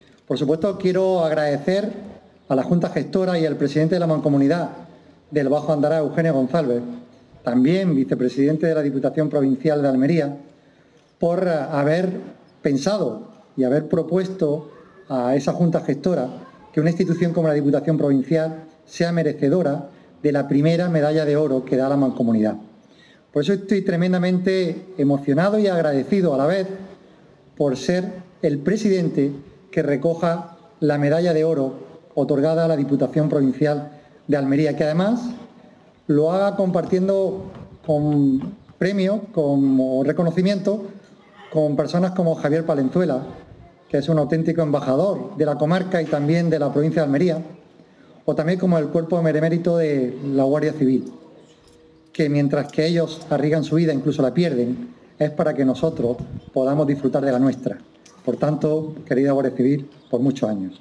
El acto, que coincide con el 39 aniversario de la creación de la Mancomunidad, se ha llevado a cabo en el Parque Bayyana de Pechina.
04-06_pechina_pte._medalla_oro.mp3